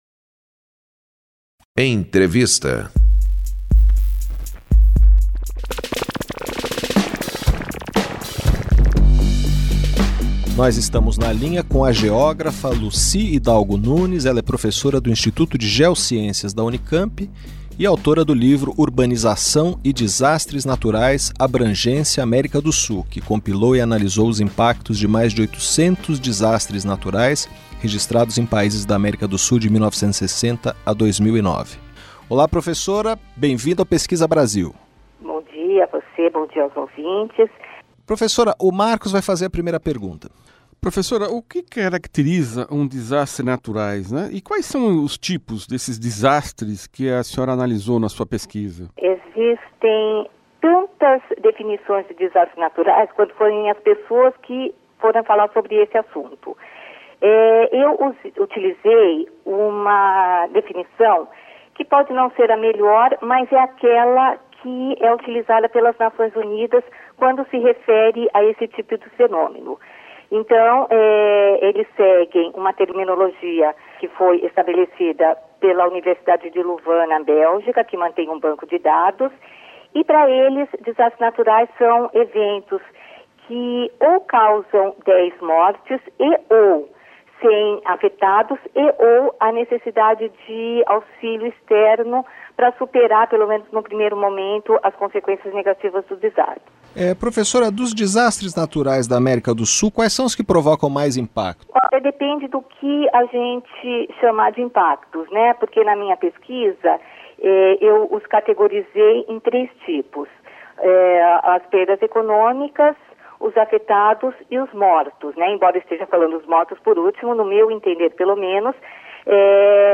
Pesquisa Brasil vai ao ar todas as sextas-feiras às 13:00, pela Rádio USP .